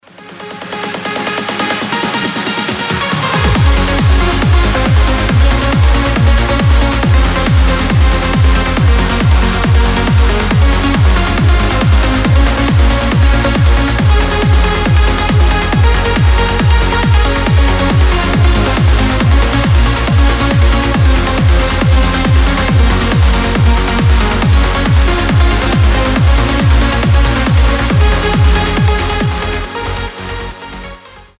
• Качество: 320, Stereo
громкие
мощные
EDM
без слов
энергичные
динамичные